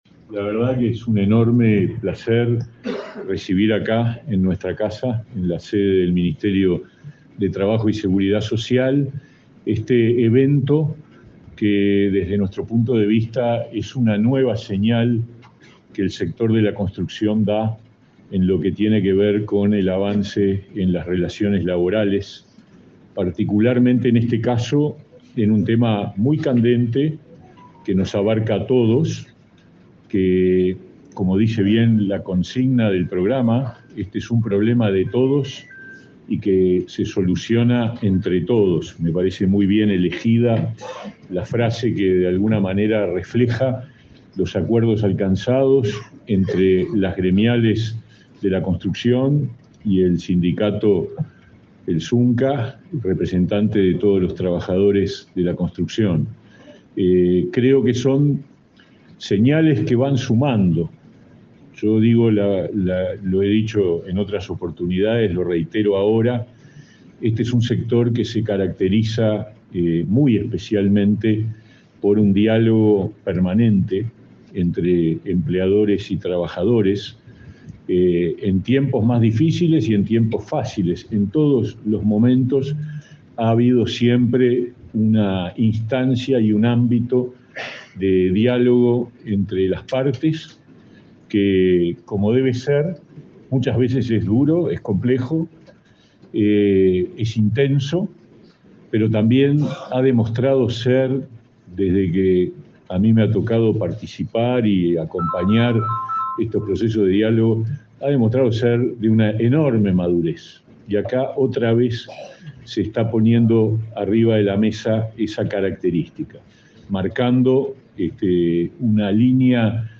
Palabras de autoridades en acto en Ministerio de Trabajo
Palabras de autoridades en acto en Ministerio de Trabajo 20/10/2023 Compartir Facebook X Copiar enlace WhatsApp LinkedIn Este viernes 20 en Montevideo, el ministro de Trabajo, Pablo Mieres, y el titular de la secretaría nacional de Drogas, Daniel Radío, participaron en el lanzamiento de la campaña de prevención del uso de drogas en el ámbito laboral en el sector de la construcción.